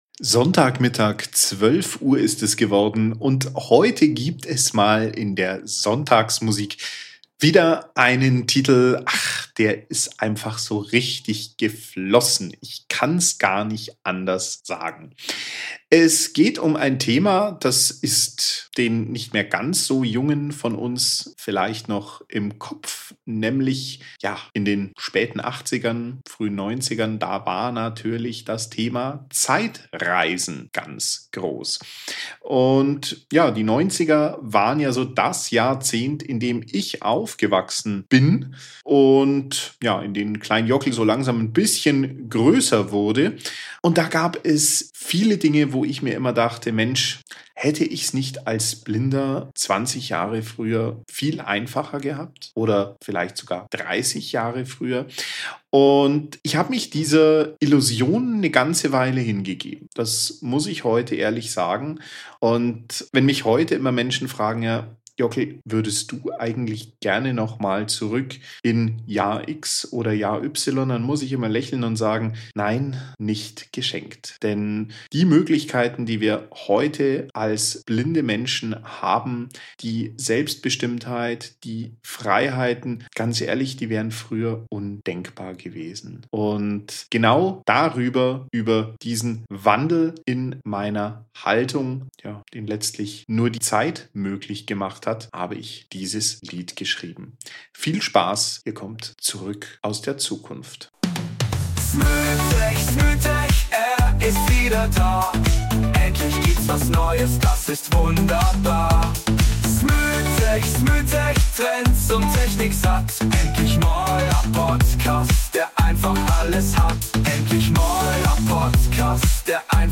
- motivierende, nachdenkliche Musik mit Tiefgang liebst